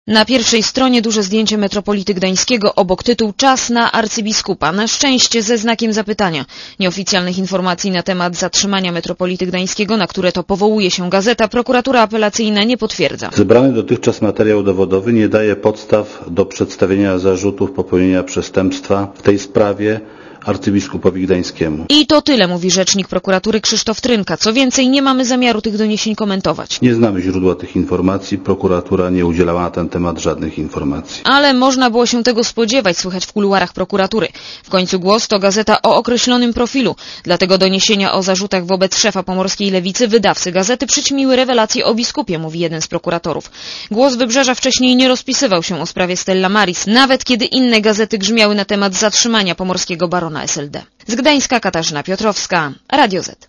Posłuchaj relacji reporterki Radia Zet (207 KB)